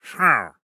Minecraft Version Minecraft Version 25w18a Latest Release | Latest Snapshot 25w18a / assets / minecraft / sounds / mob / wandering_trader / no1.ogg Compare With Compare With Latest Release | Latest Snapshot